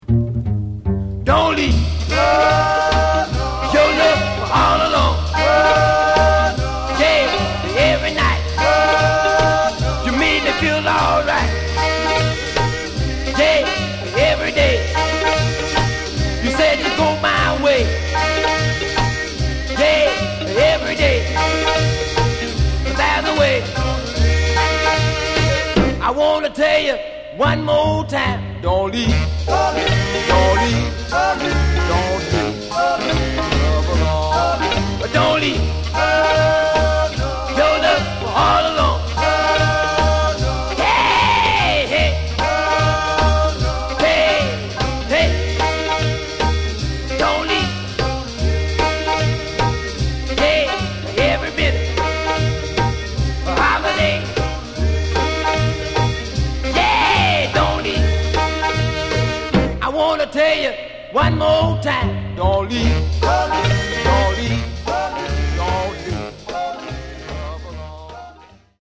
Genre: Deep Soul